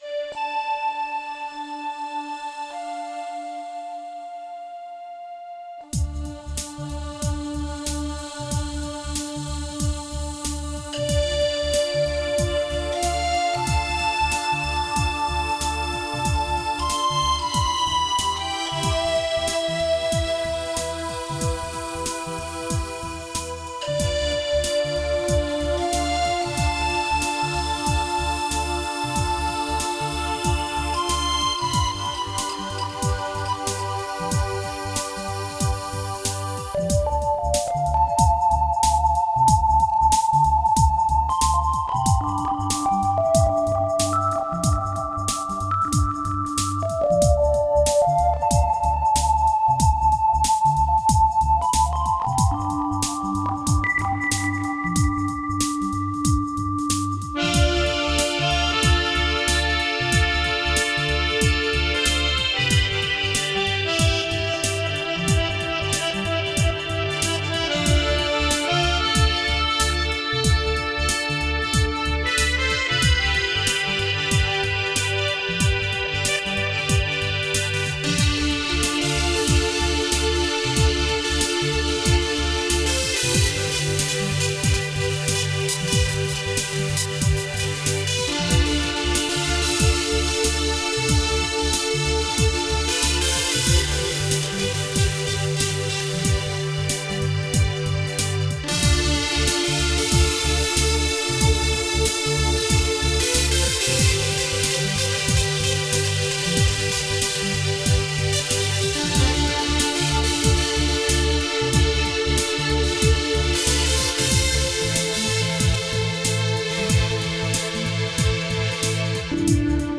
Духовная музыка Мистическая музыка Медитативная музыка